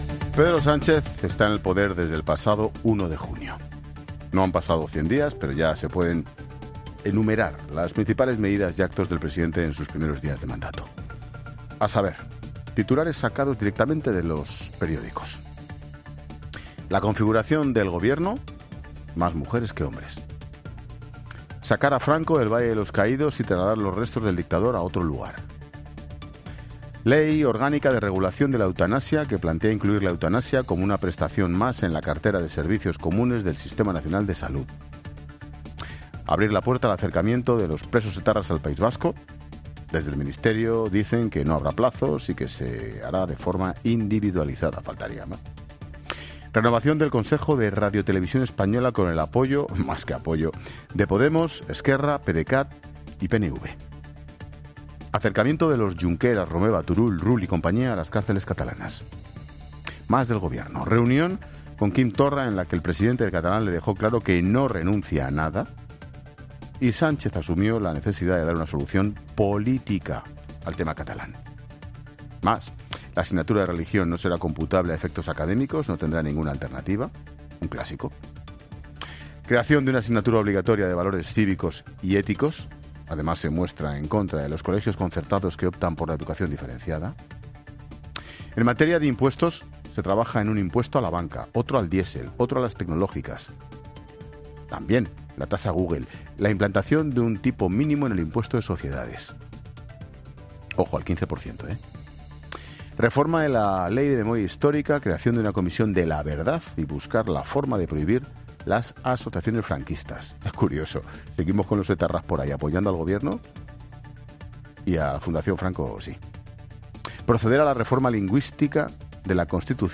Monólogo de Expósito
Comentario de Ángel Expósito enumerando las medidas de Pedro Sánchez en sus primeras semanas como Presidente.